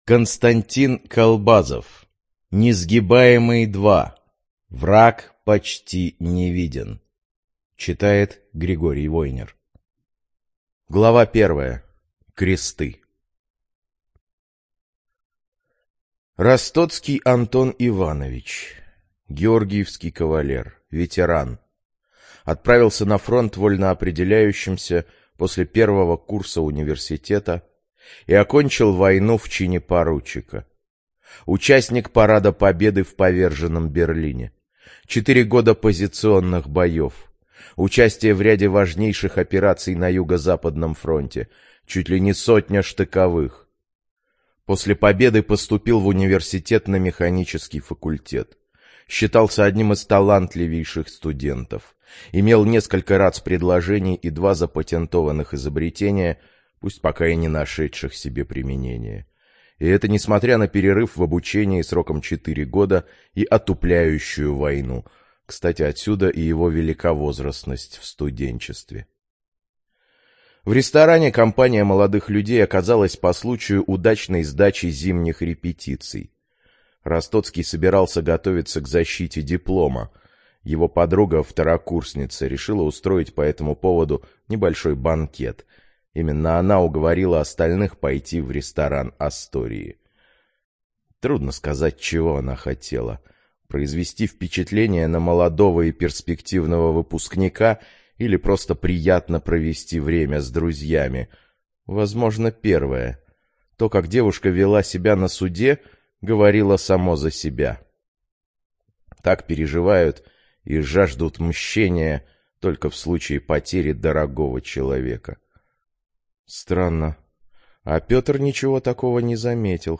Информация о книге Несгибаемый-02.